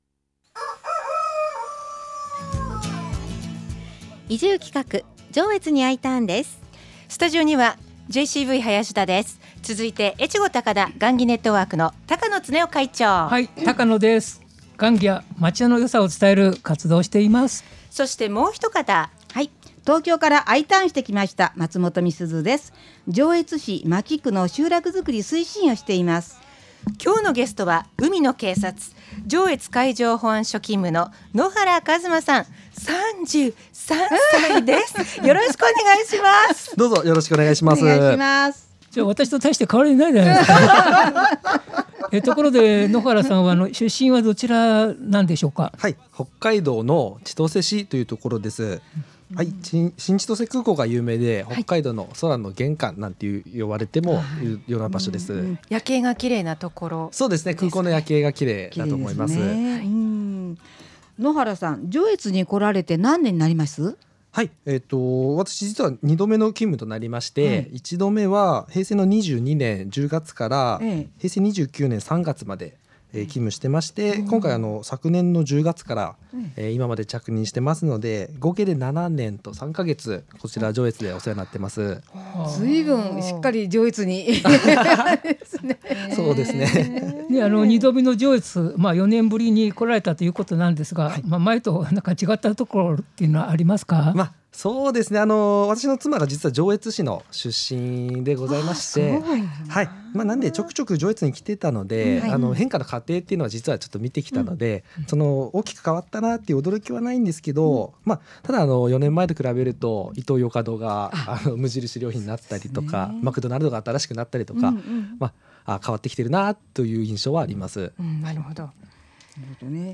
このコーナーは、上越・妙高・糸魚川市への移住をお誘いするコーナーです。 第271回2021年7月14日放送分の放送をアップしました。